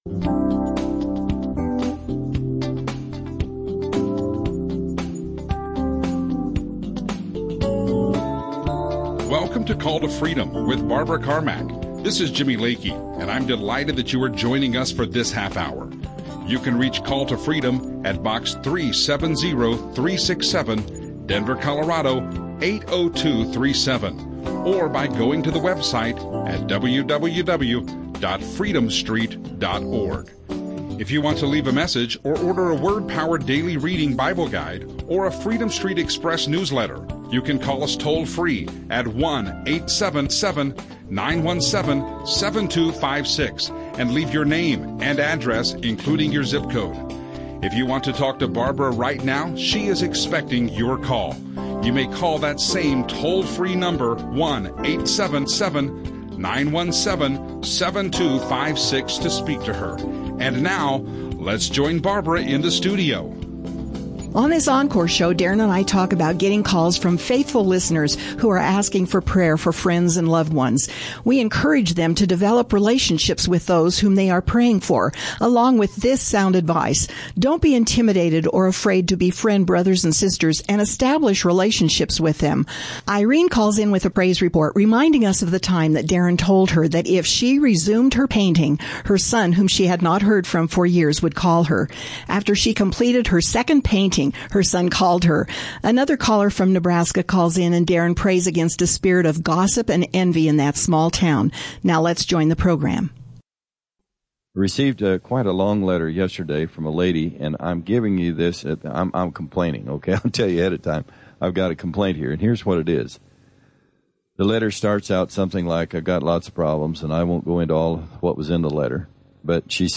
They have great phone calls from Colorado Springs and Nebraska, answers to prayer and prayer requests centering in on gossip in her small town.
Christian radio